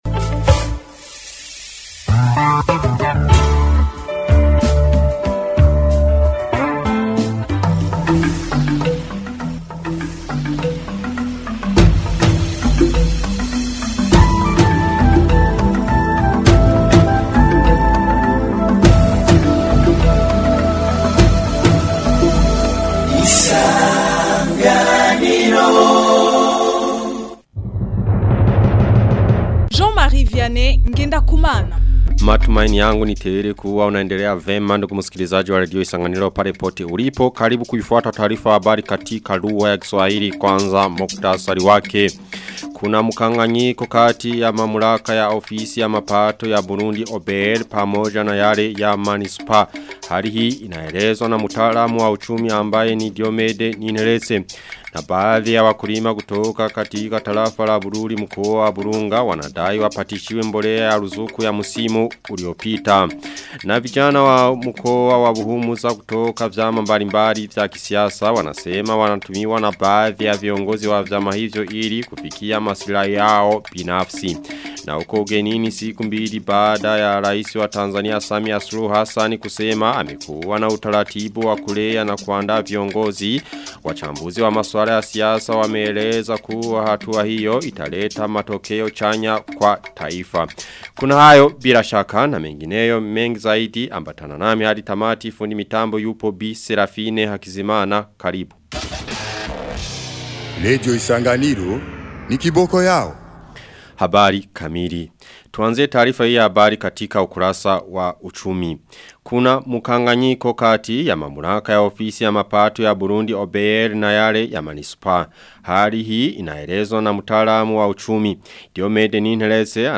Taarifa ya habari ya tarehe 11 Februari 2026